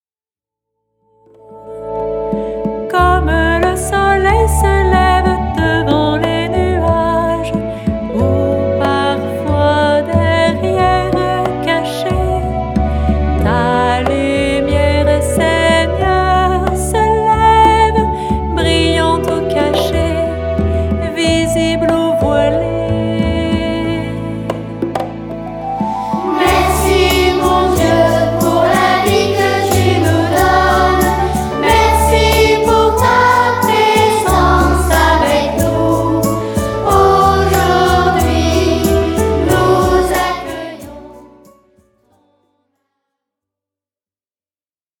10 chants rythmés et enjoués